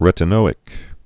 (rĕtn-ōĭk)